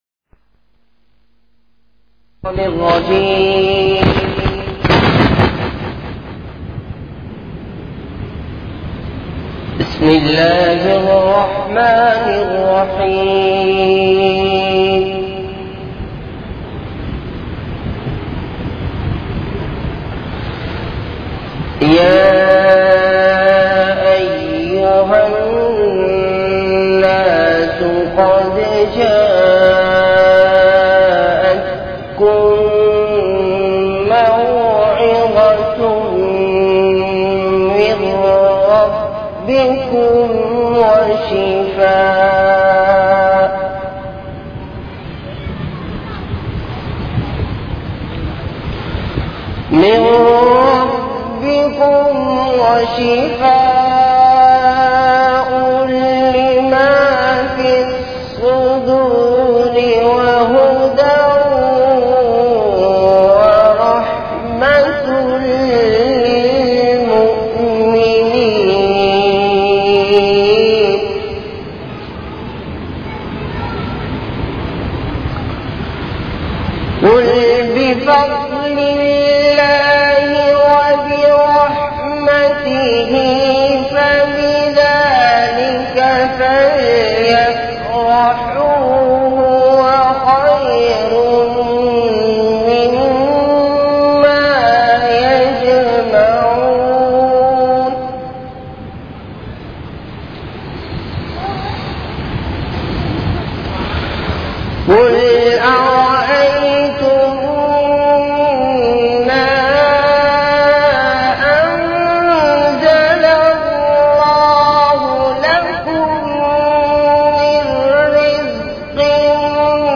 Delivered at Apsara Appartments.
Bayanat